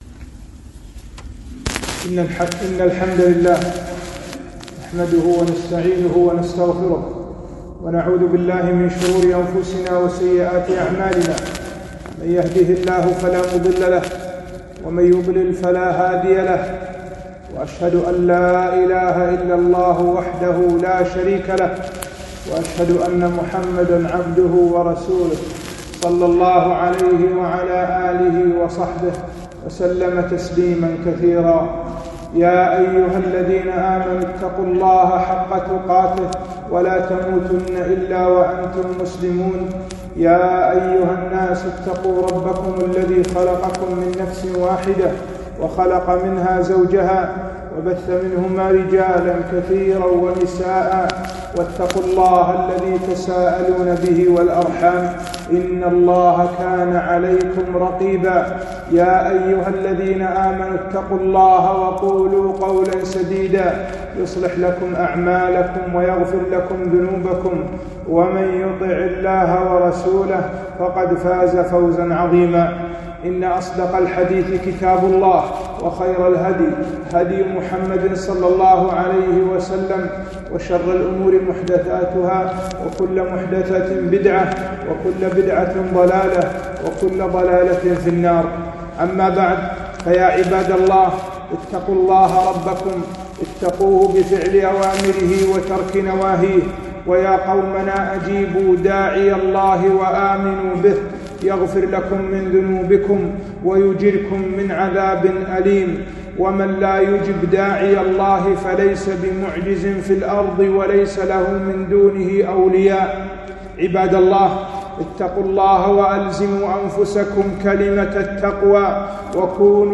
خطبة - الرجوع إلى الله